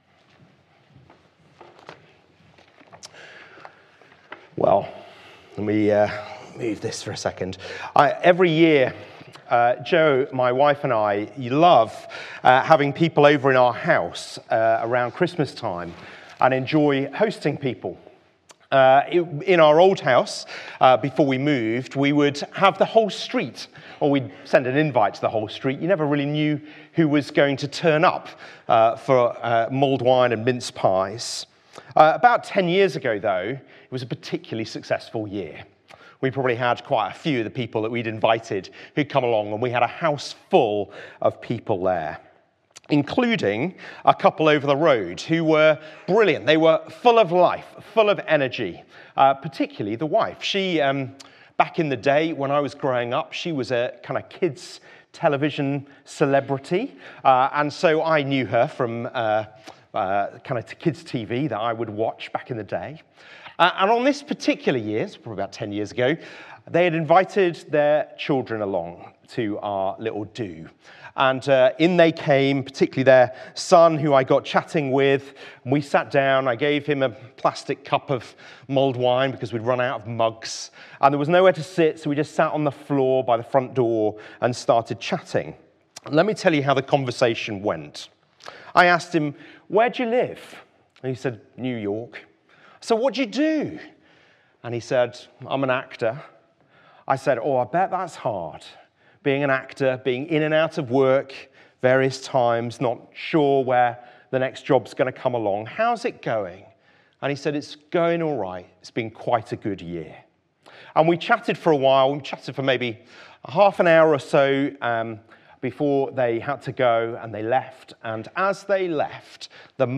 Traditional carol service 14 December | Beeston Free Church
Carol Service